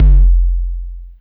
53 BD 1   -L.wav